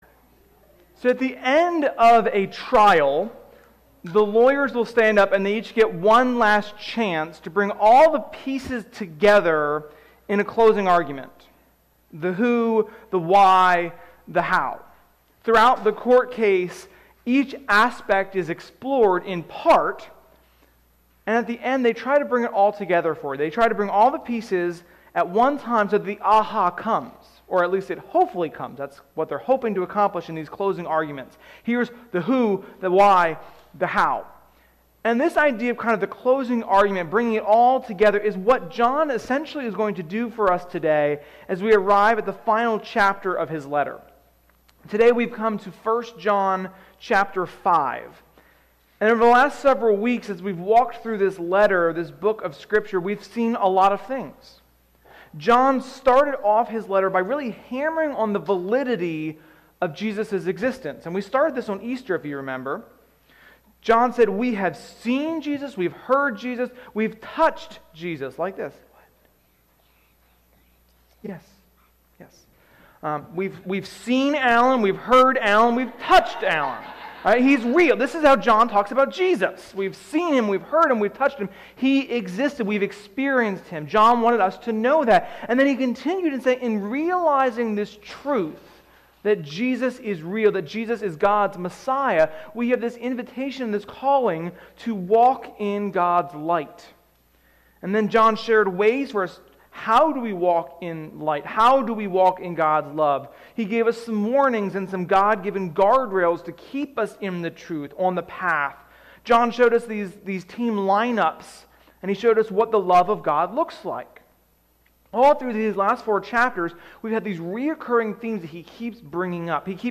Sermon-5.16.21.mp3